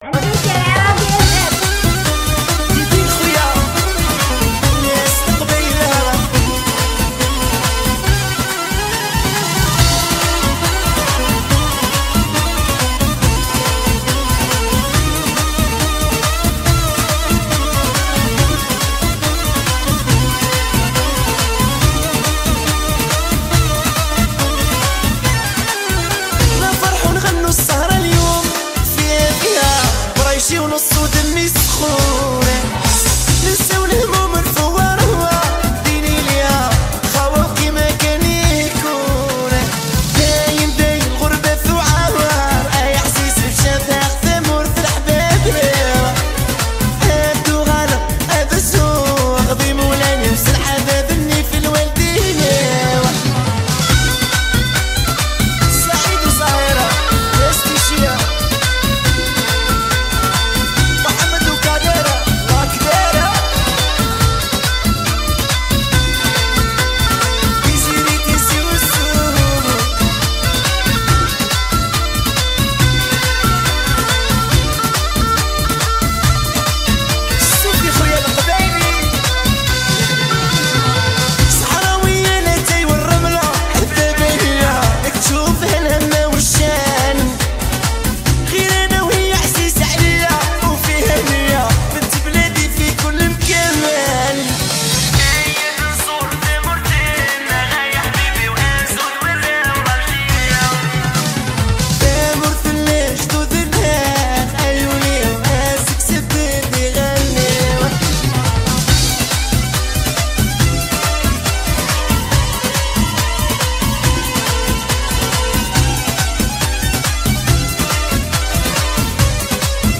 اغاني هجوله مغربيه